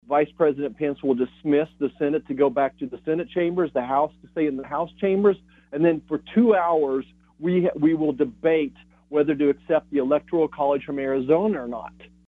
Senator Marshall joined in the KSAL Morning News Extra and gave listeners a look behind the scenes at how the process could roll out Wednesday afternoon as results from some narrowly won states are contested, like Arizona.